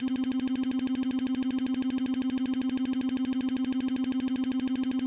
text-scroll-c5-180-openmpt-voice-oohs.wav